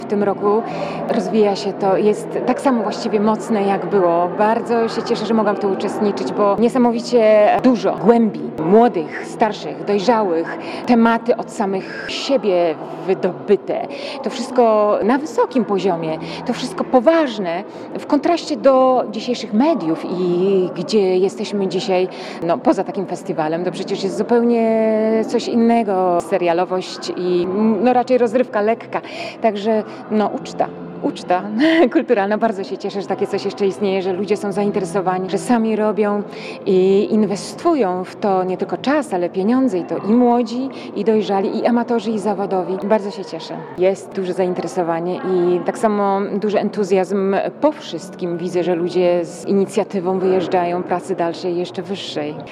setka-uczestnika.mp3